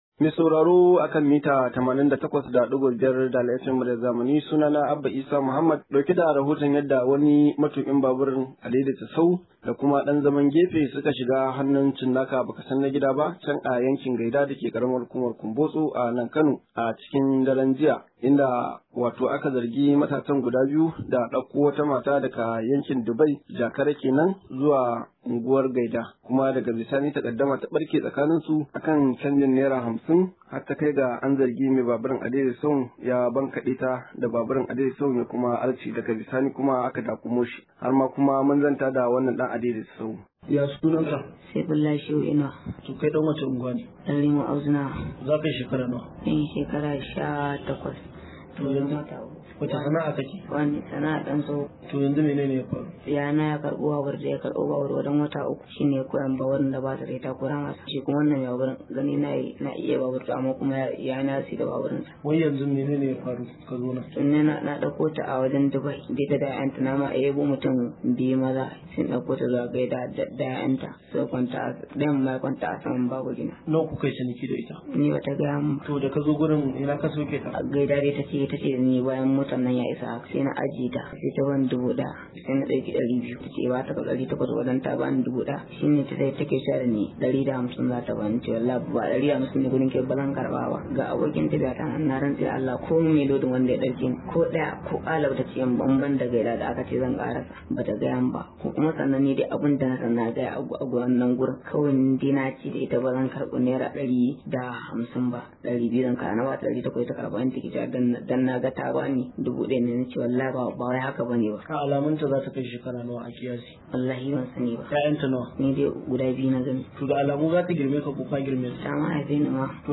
Rahoto: Direban Adaidaita Sahu ya tintsirar da wata mata akan canjin Naira 50